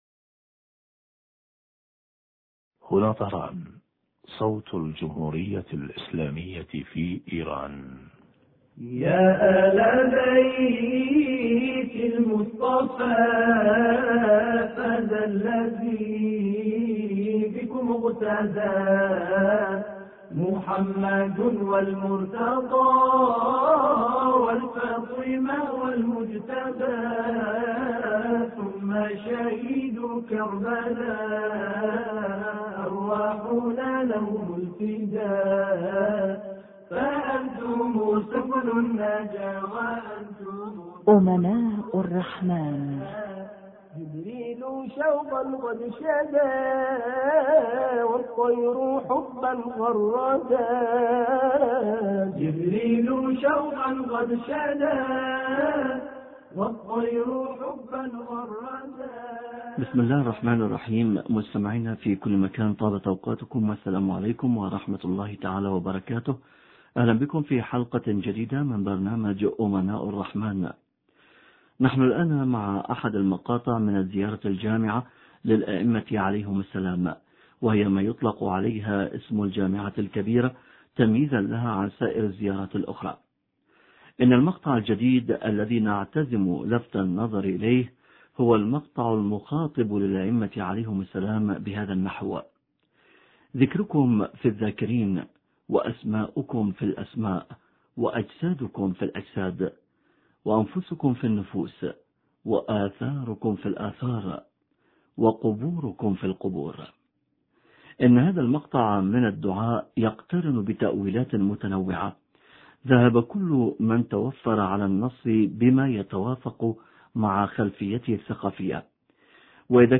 وذلك في الاتصال الهاتفي التالي الذي اجراه زميلنا